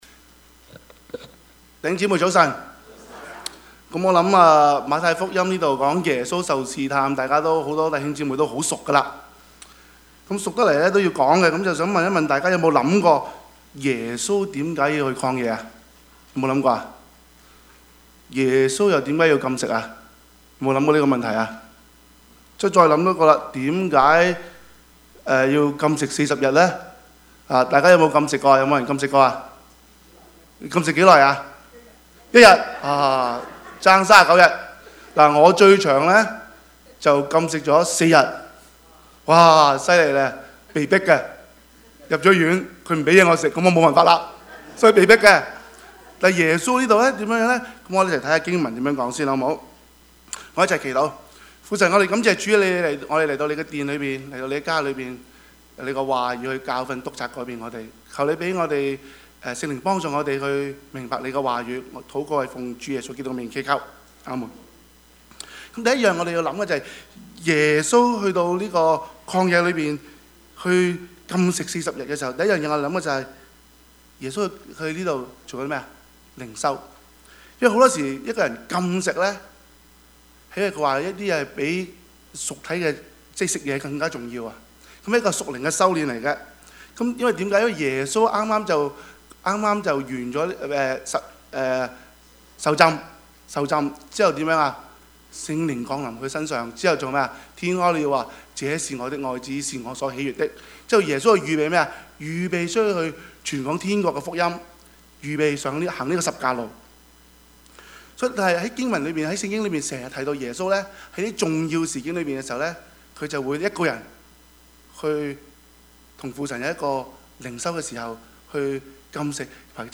Passage: 馬太福音 4:1-11 Service Type: 主日崇拜
Topics: 主日證道 « 巴拿巴與掃羅 男人大丈夫 »